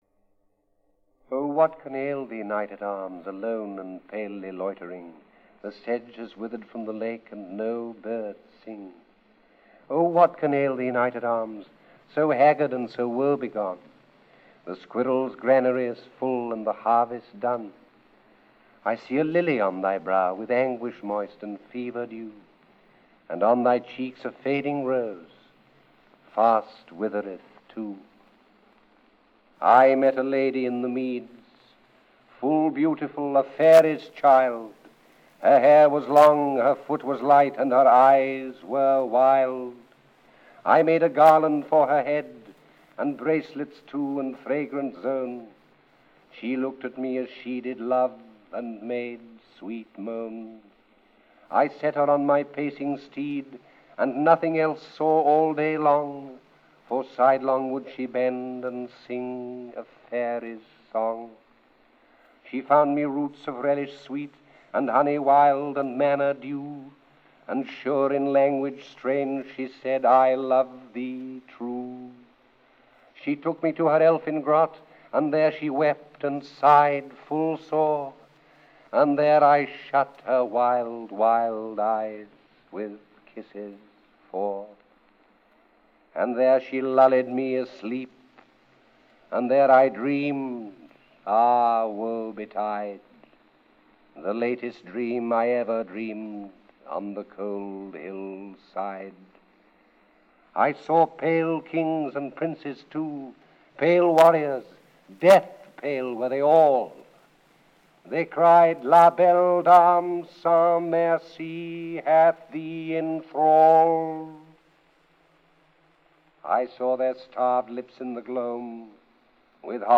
When his health made it difficult for him to pursue acting roles, he began to record favourite poems in his home.